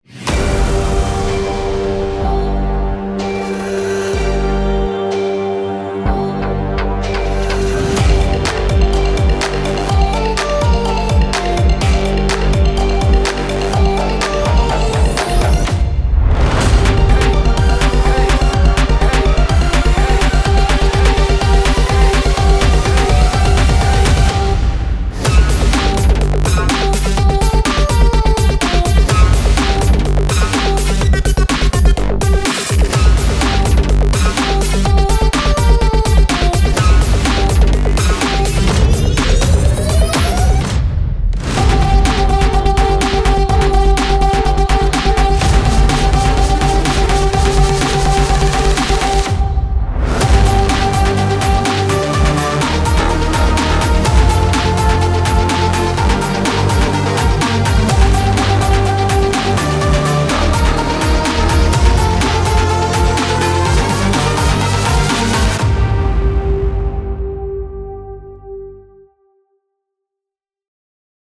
Curated modern hybrid synths
• Modern, punchy hybrid synth sounds for electronic music